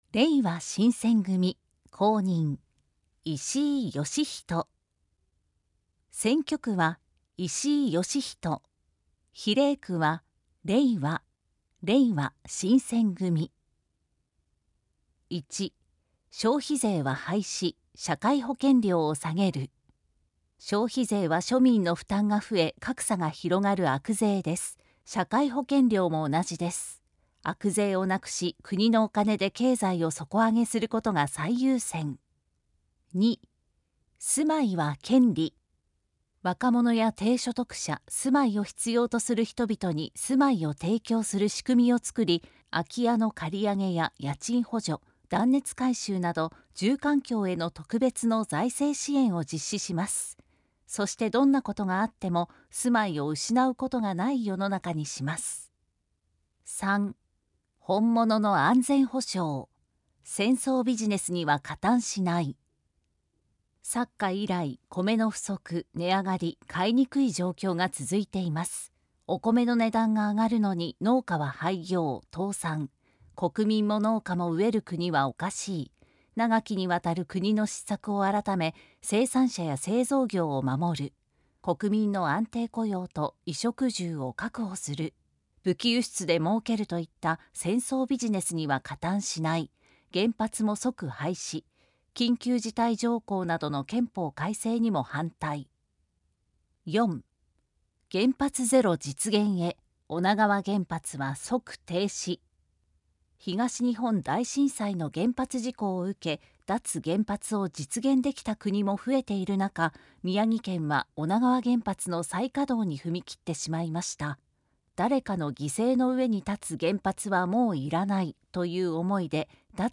参議院議員通常選挙候補者・名簿届出政党等情報（選挙公報）（音声読み上げ用）（音声版）